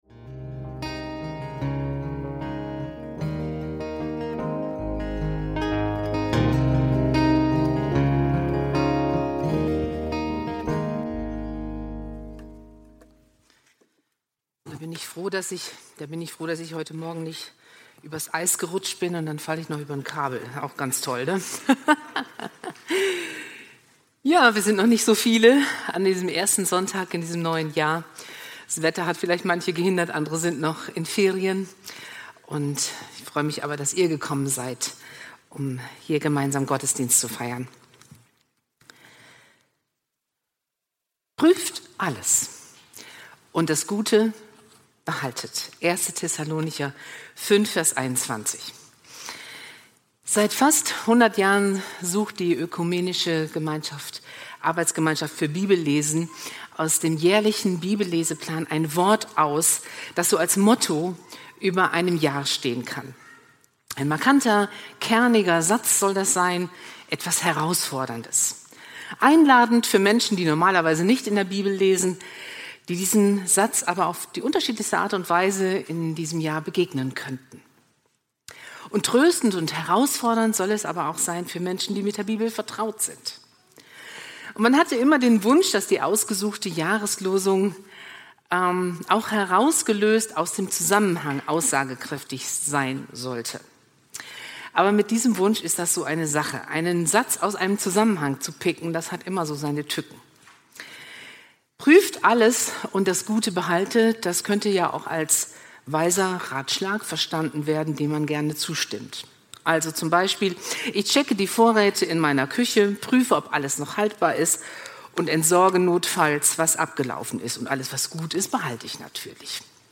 Prüft alles und behaltet das Gute – Predigt zur Jahreslosung vom 05.01.2025